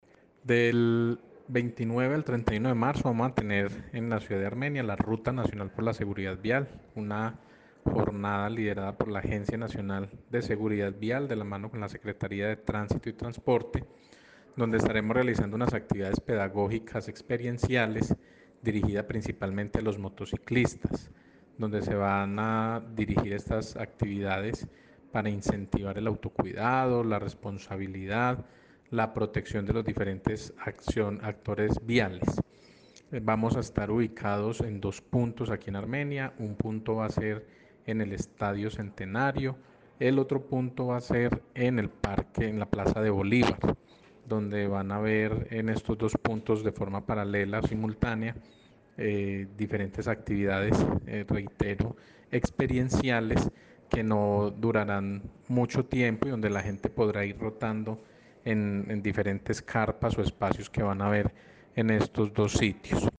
Audio: Daniel Jaime Castaño Calderón – Secretario de Tránsito y Transporte de Armenia – SETTA